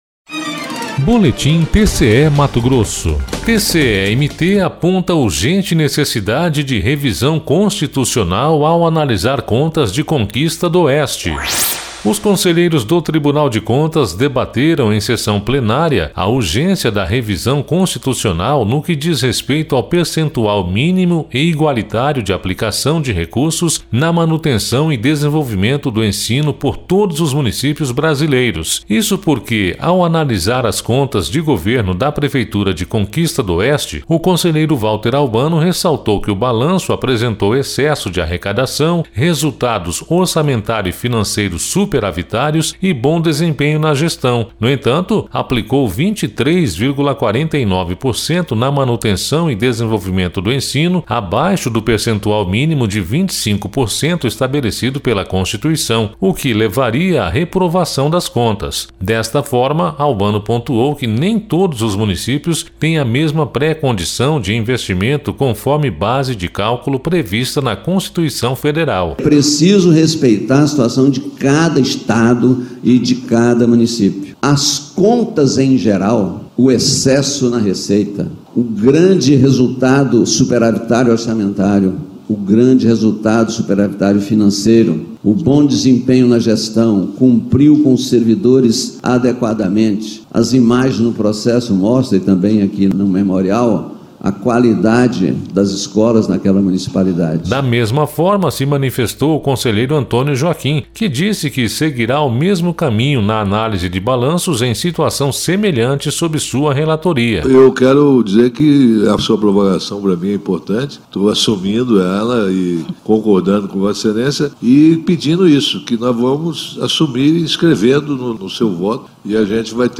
Sonora: Valter Albano – conselheiro do TCE-MT
Sonora: Antonio Joaquim – conselheiro do TCE-MT
Sonora: Sérgio Ricardo – conselheiro do TCE-MT
Sonora: Alisson Carvalho de Alencar - procurador-geral do MPC-MT